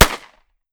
9mm Micro Pistol - Gunshot B 003.wav